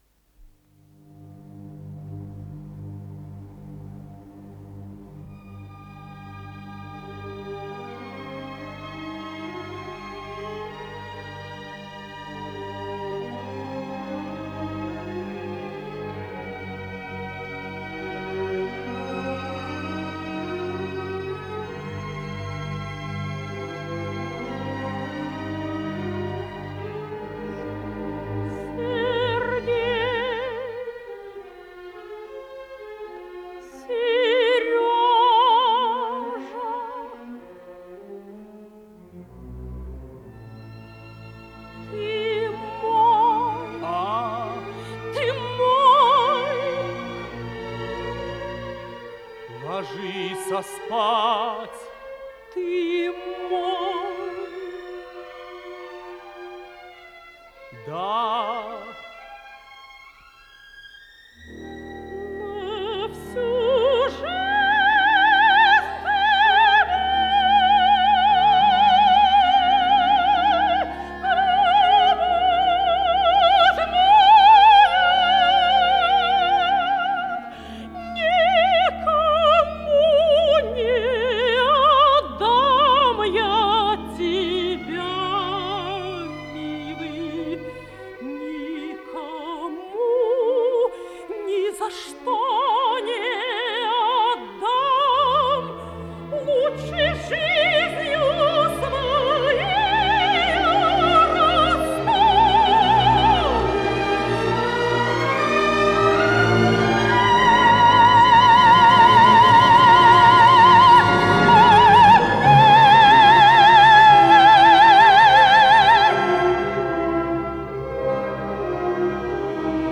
Опера в 4-х актах, 9-ти картинах